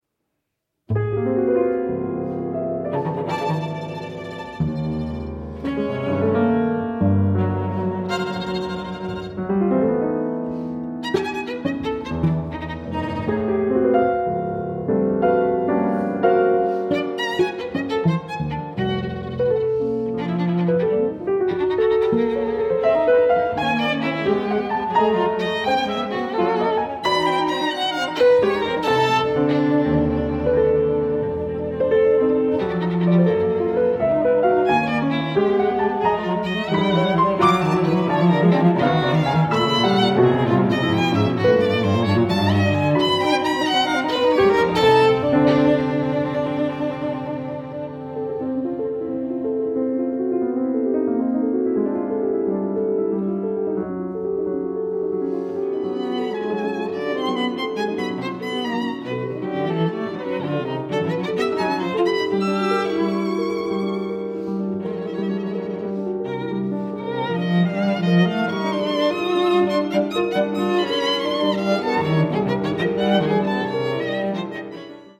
chamber works for various instrumentations
Allegro